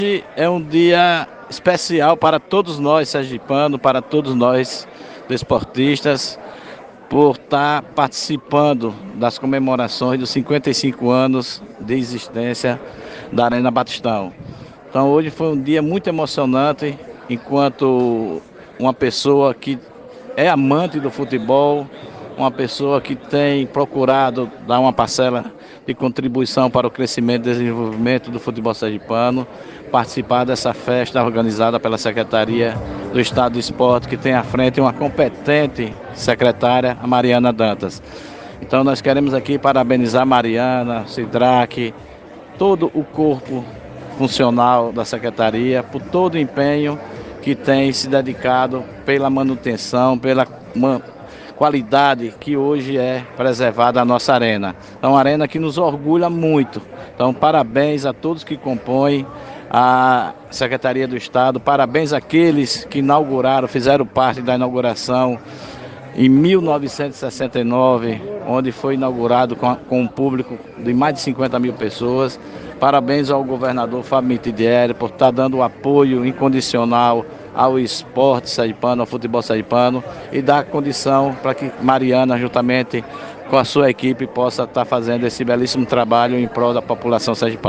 Em homenagem ao aniversário de 55 anos do Estádio Lourival Baptista, o Batistão, o Governo de Sergipe, por meio da Secretaria de Estado do Esporte e Lazer (Seel), realizou uma celebração na manhã desta terça-feira, 9.